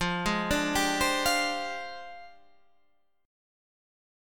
F Minor 6th Add 9th